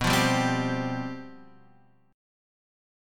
Csus/B chord